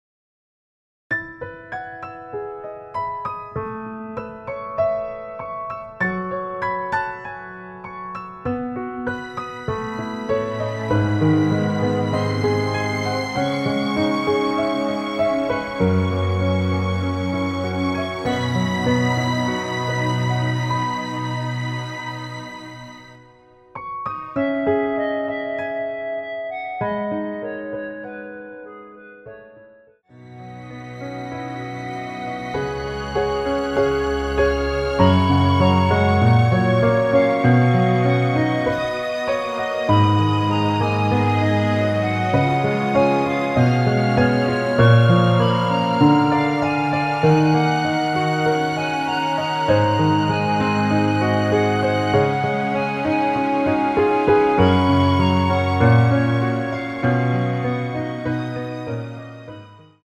원키에서(+4)올린 멜로디 포함된 MR입니다.
C#m
앞부분30초, 뒷부분30초씩 편집해서 올려 드리고 있습니다.
중간에 음이 끈어지고 다시 나오는 이유는
(멜로디 MR)은 가이드 멜로디가 포함된 MR 입니다.